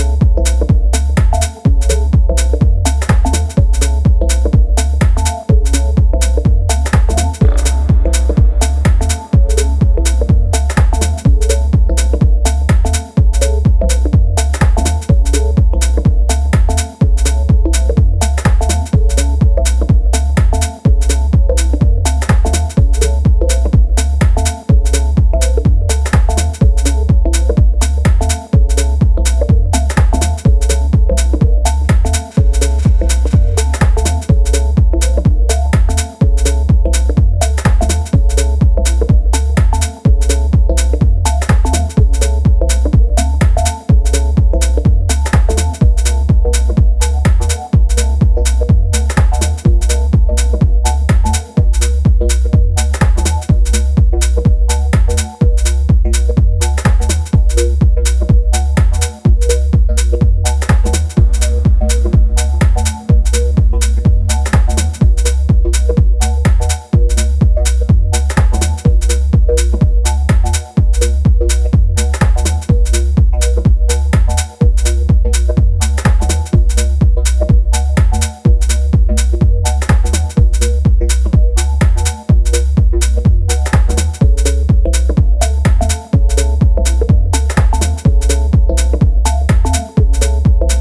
an exercise in driving bass minimalism.